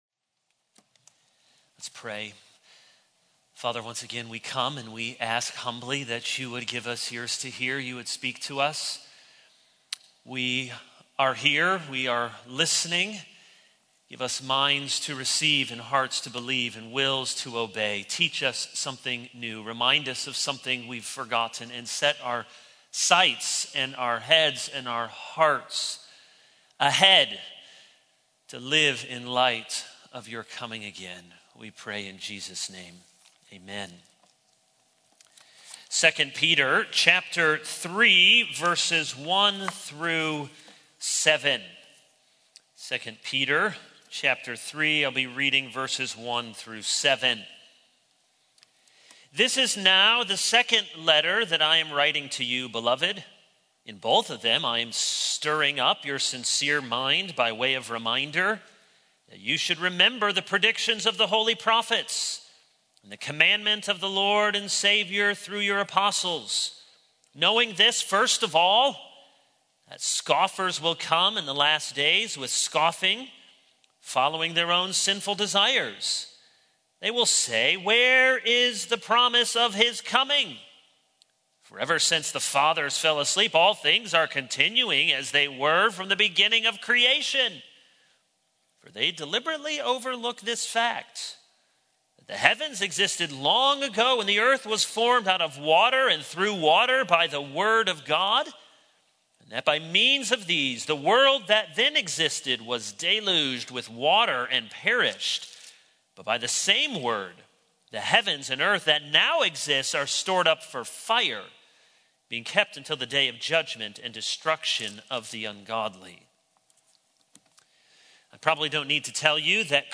All Sermons A Prayer of Destruction 0:00 / Download Copied!